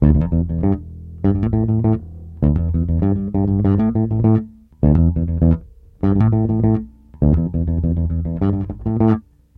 SONS ET LOOPS GRATUITS DE BASSES DANCE MUSIC 100bpm
Basse dance 1